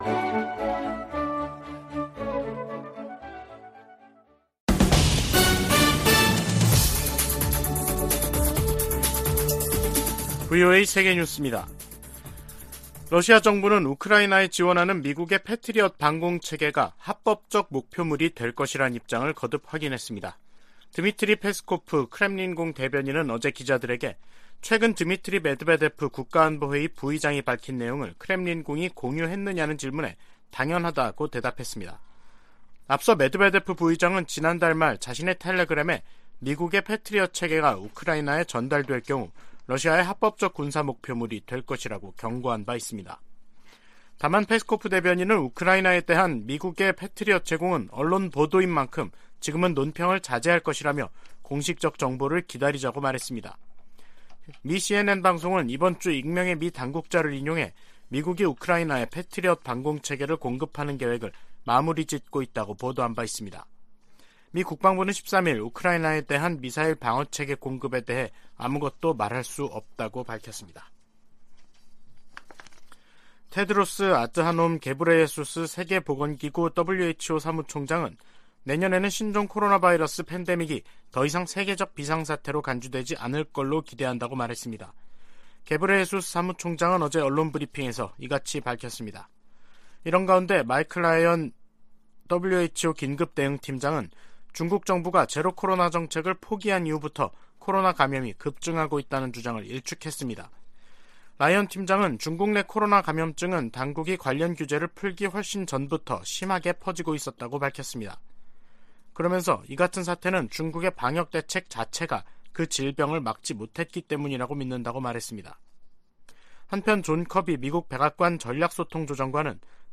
VOA 한국어 간판 뉴스 프로그램 '뉴스 투데이', 2022년 12월 15일 3부 방송입니다. 북한 김정은 정권이 주민을 착취해 무기 프로그램을 증강하고 있다고 국무부가 지적했습니다. 미국 의회가 새 국방수권법 합의안에서 핵전력을 현대화하고 미사일 방어를 강화하기 위한 예산을 계속 지원하기로 했습니다.